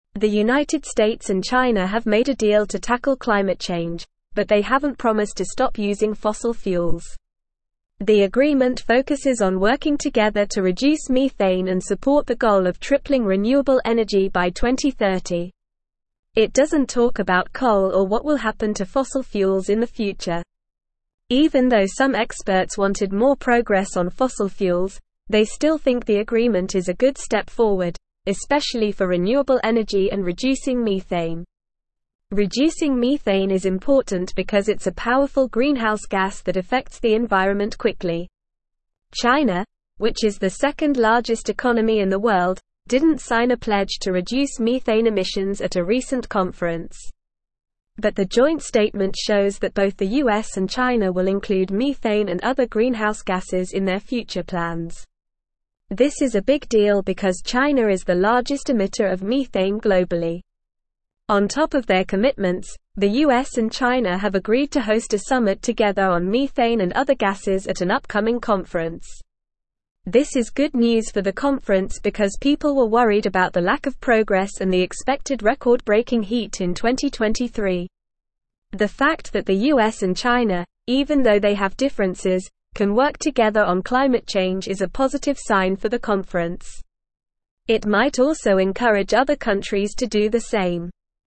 Normal
English-Newsroom-Upper-Intermediate-NORMAL-Reading-US-and-China-Reach-Climate-Agreement-Address-Methane.mp3